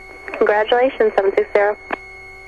I got clearance to taxi back to park the plane, and then, unexpectedly, the tower controller gave me her congratulations: